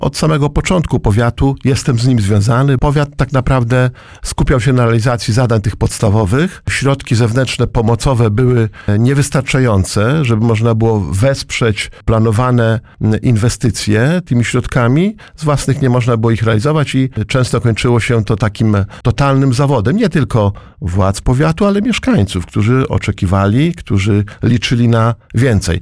Był to niesamowicie pracowity czas – mówił na naszej antenie starosta powiatu łomżyńskiego, Lech Szabłowski o mijającej 5-letniej kadencji.